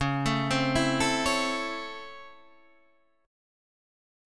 C#mM7#5 chord